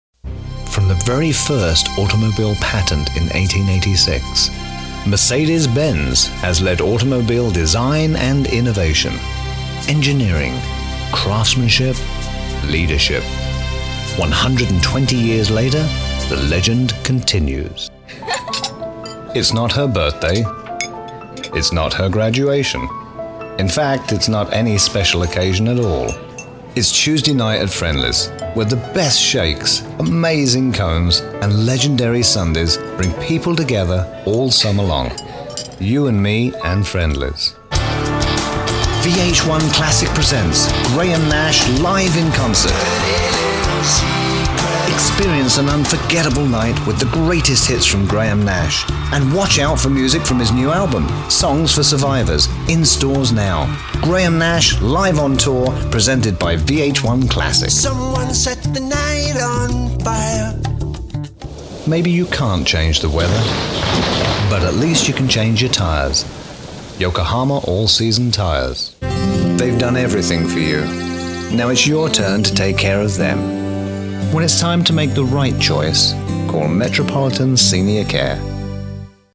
BRITISH ENGLISH DISTINCT ALL ENGLISH ACCENTS, SEXY, SMOOTH,DEEP, INVITING British voice, Classy, Conversational, informative, interesting, Commanding, Believable, Smooth, hard sell.
Sprechprobe: Werbung (Muttersprache):